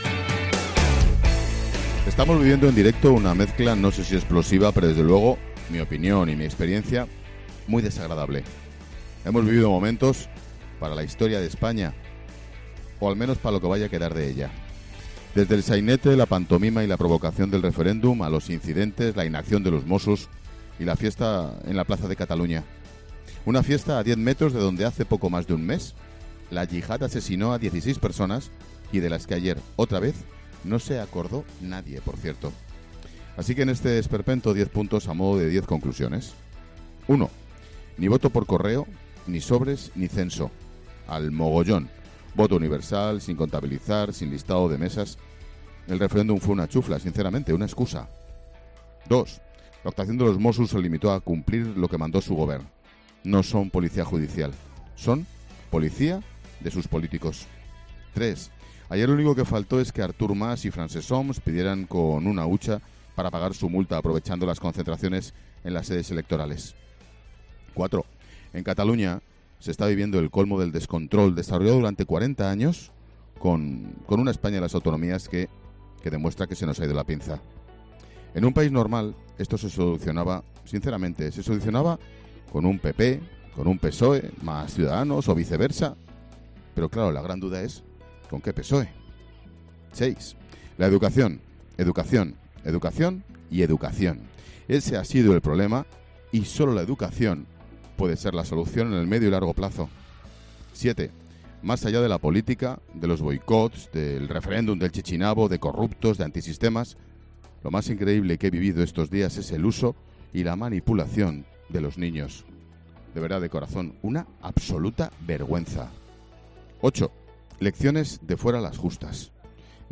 Monólogo de Expósito
Ángel Expósito desde Barcelona, después de vivir el referéndum ilegal en Cataluña.